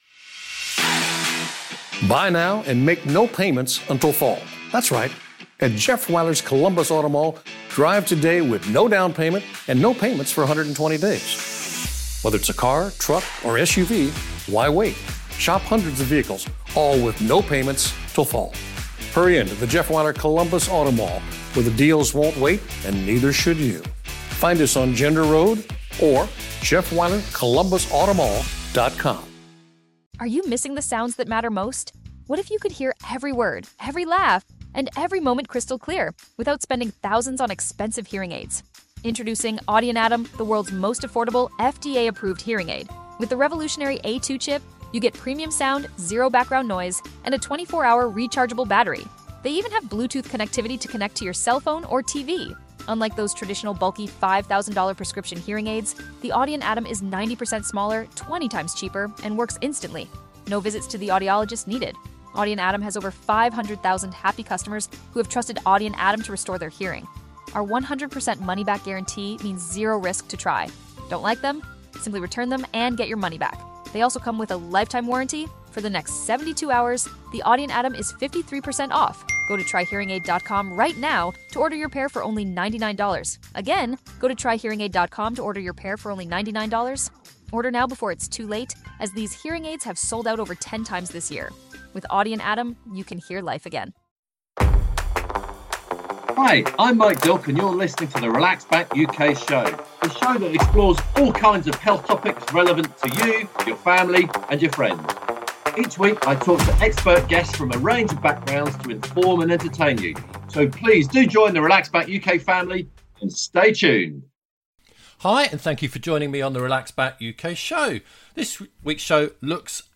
Episode 125 - TV presenter Helen Skelton talks about hygiene poverty then some surprising oral health tips.